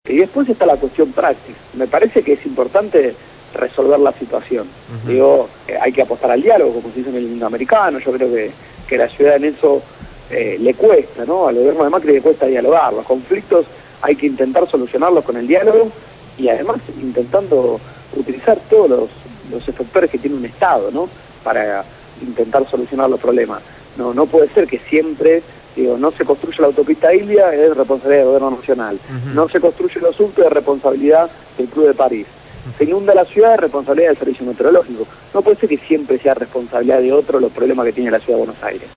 Lo dijo Gonzalo Ruanova, diputado de la Ciudad de Buenos Aires, integrante del bloque Nuevo Encuentro; en el programa «Punto de partida» (Lunes a viernes de 7 a 9 de la mañana) por Radio Gráfica.